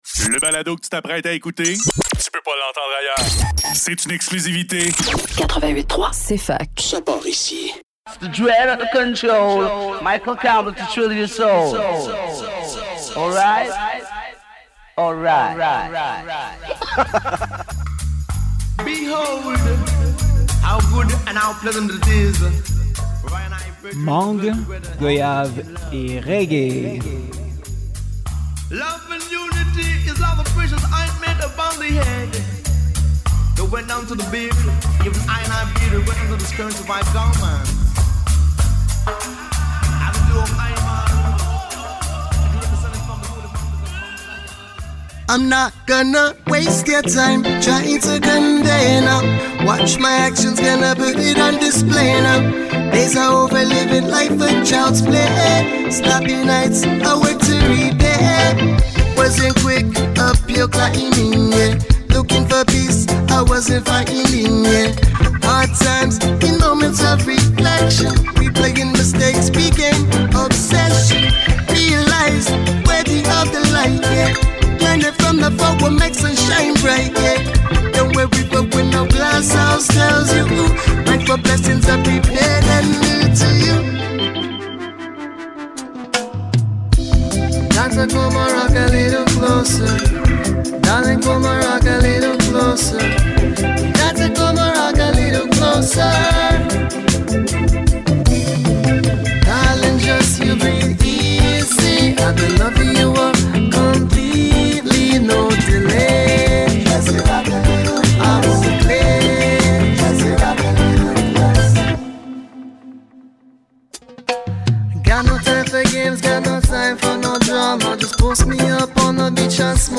CETTE SEMAINE: Reggae contemporain